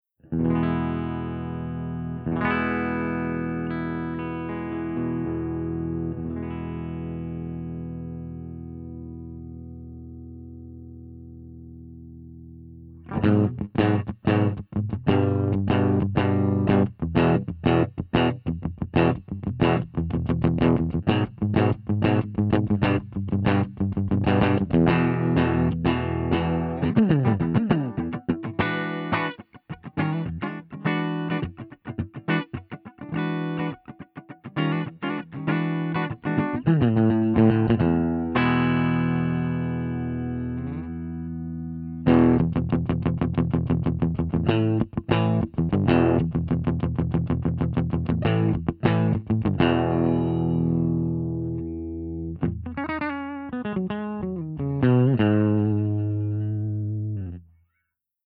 039_FENDER75_WARM_BASSBOOST_SC.mp3